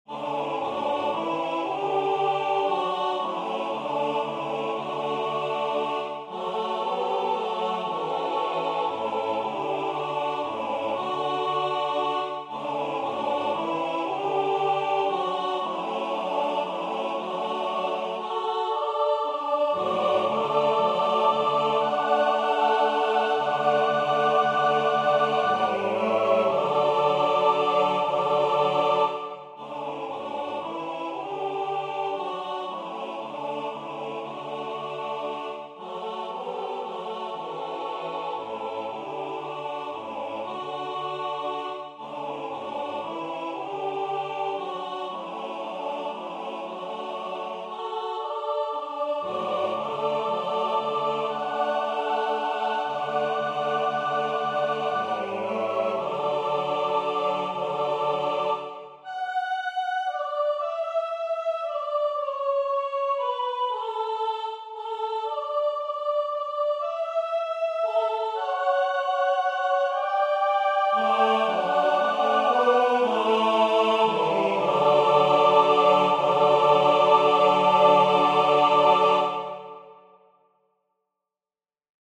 A setting of a C16th manuscript - SATB unaccompanied.